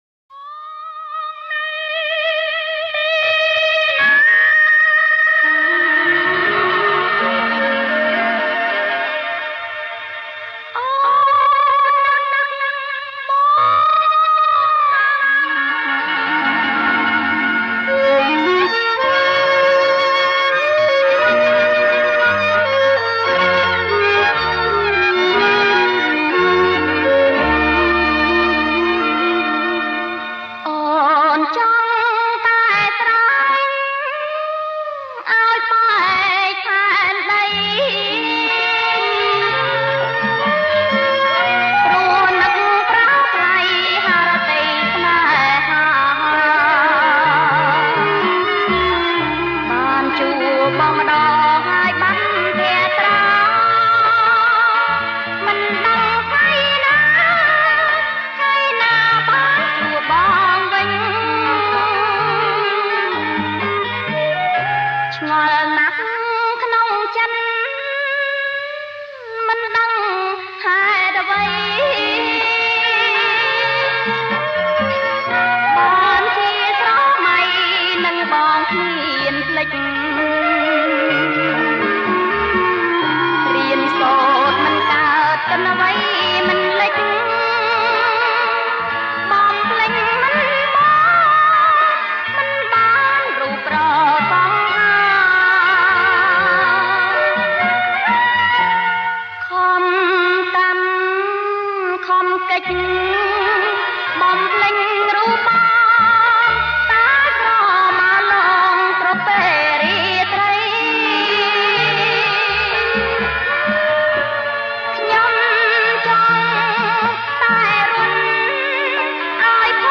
• ប្រគំជាចង្វាក់ Rumba